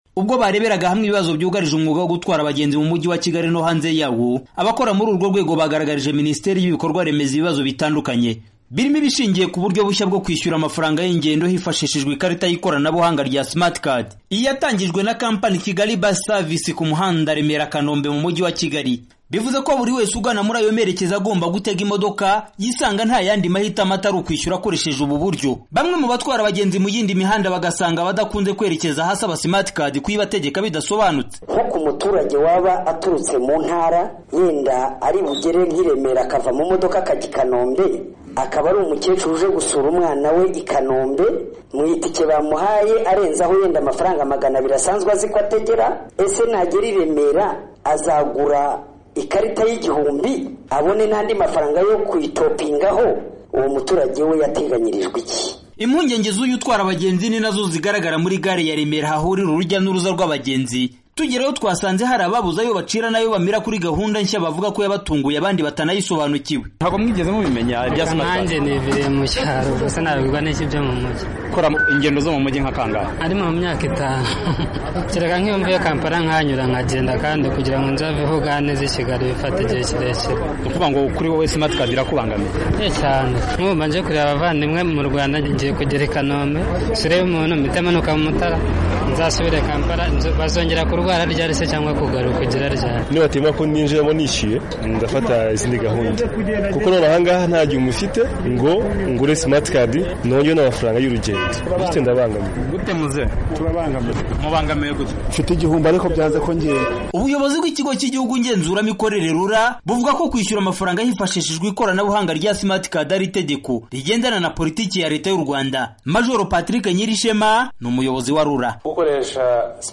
Bamwe mu batwara abagenzi ndetse n'abagenzi baravuga ko iyi gahunda ibangamiye bikomeye abadatuye muri uwo mujyi bahagenda gake.